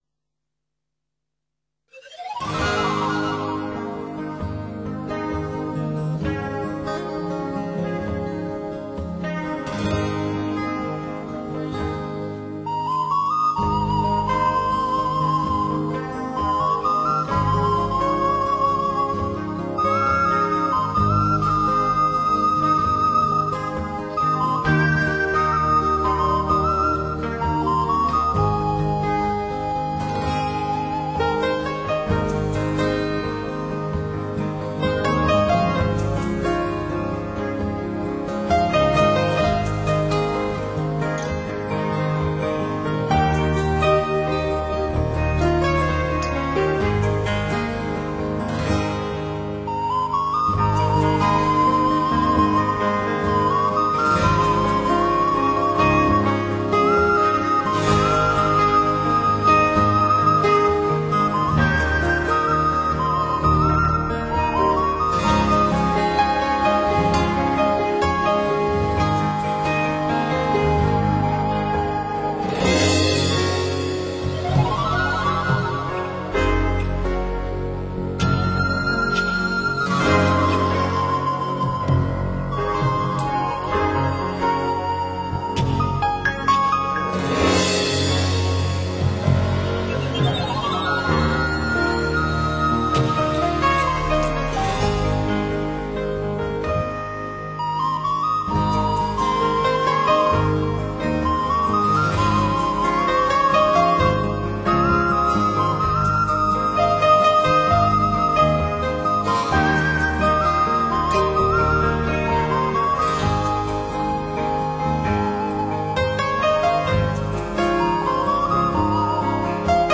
透明、神秘的音乐充满了朦胧的氛围，为人们带来内心的平静。